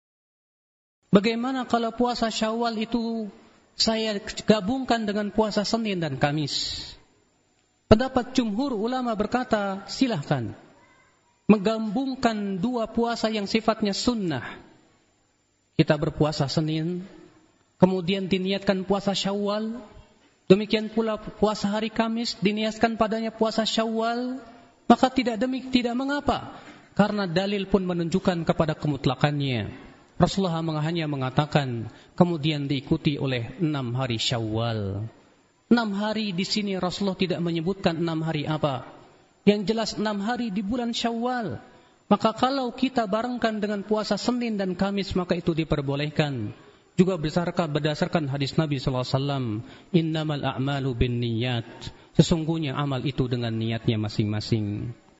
Kajian Audio